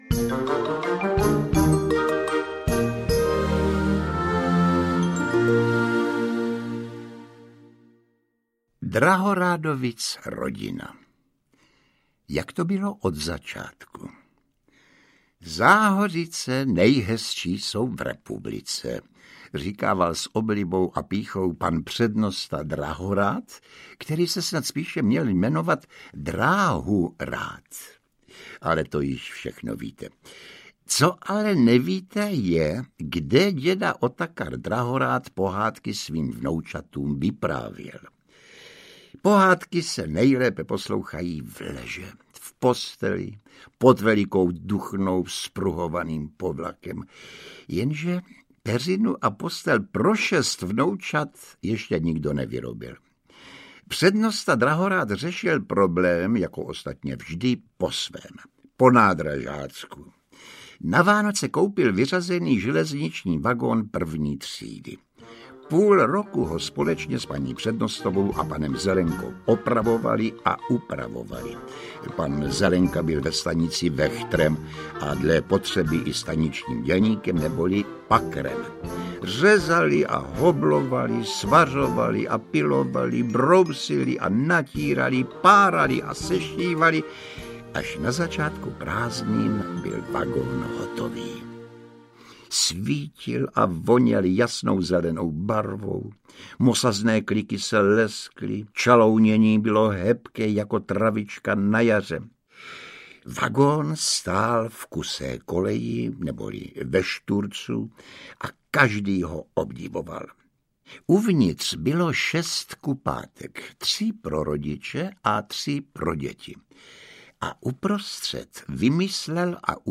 Pohádkové prázdniny u přednosty Drahoráda audiokniha
Ukázka z knihy
• InterpretJiří Somr